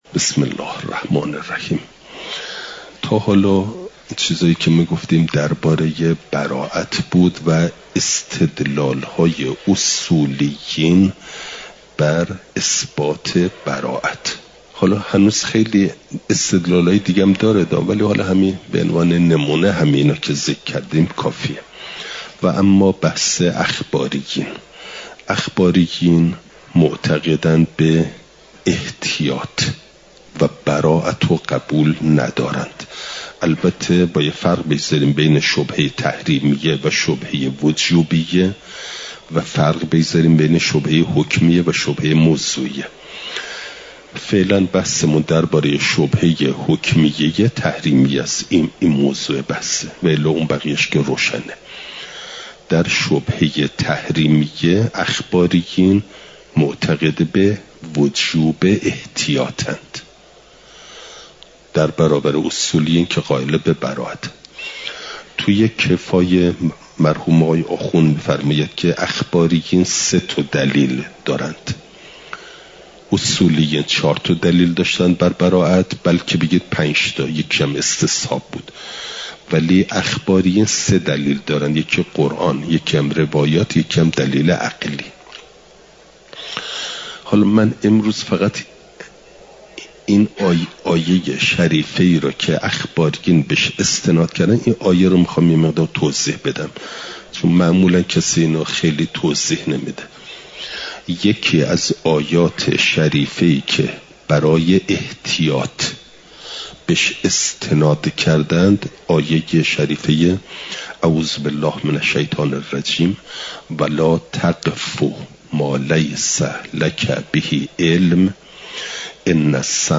اصول عملیه؛ برائت (جلسه۵۵) « دروس استاد